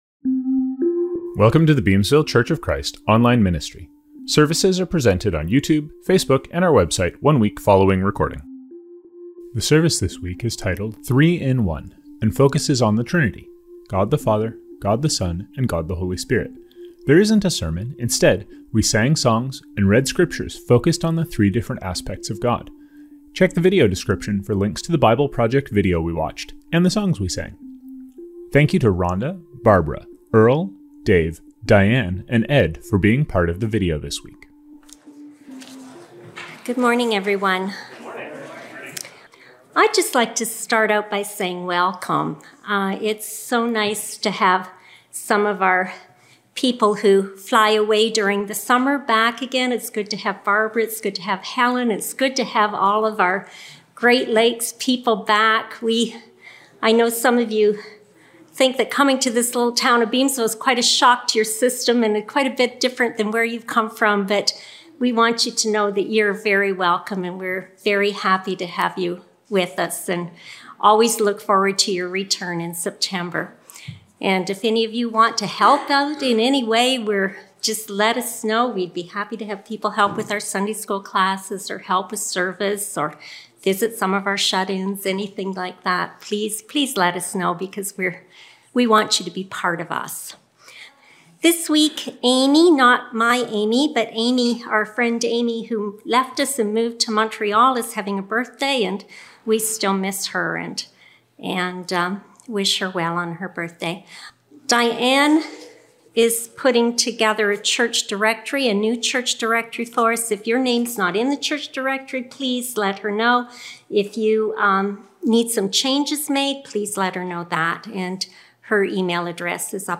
Scriptures in this service include: God the Father – Psalm 68:4-6; Isaiah 40:9-11; Isaiah 40:25-31; Psalm 23 (KJV) — God The Son – John 1:1-5, Matthew 11:27-30, 2 Corinthians 1:3-5, John 3:16-17 — Communion – 1 Corinthians 11:24-26 — God the Holy Spirit – John 14:15-17; Isaiah 61:1-3; Romans 8:12-17; John 14:26-27; Galatians 5:22-23.